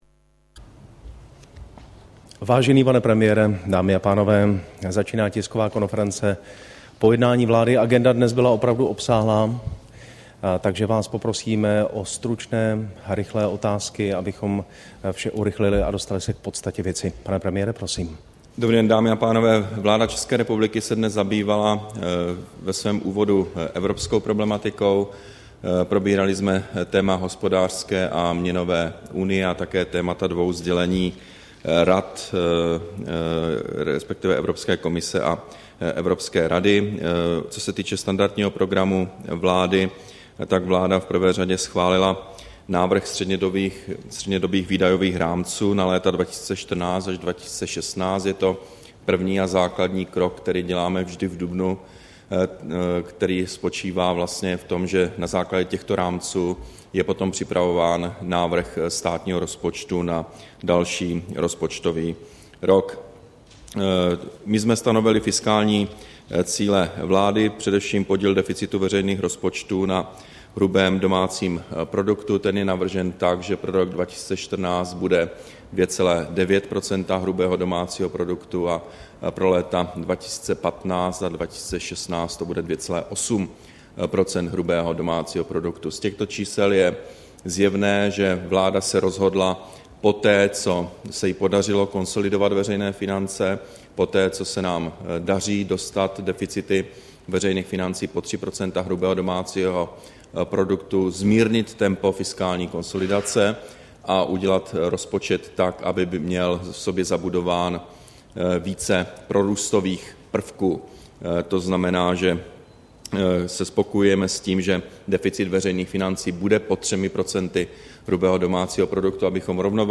Tisková konference po jednání vlády, 24. dubna 2013